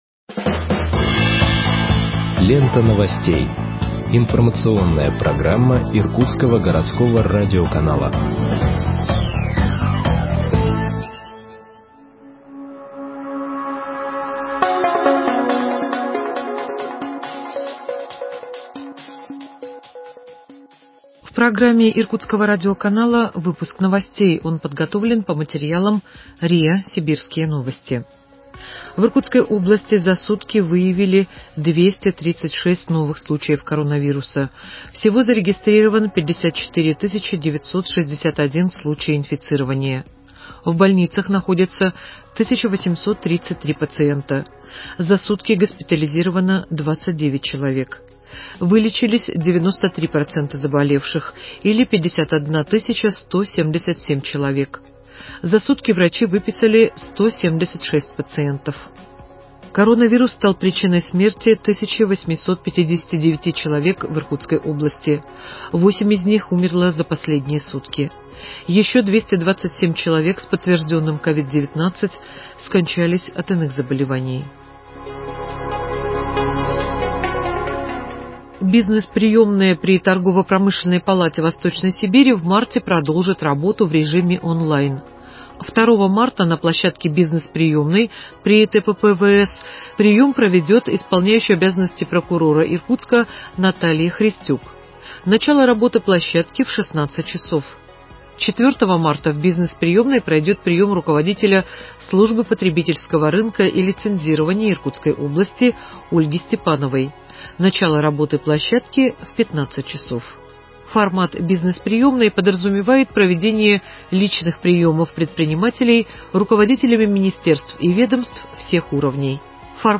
Выпуск новостей в подкастах газеты Иркутск от 24.02.2021 № 2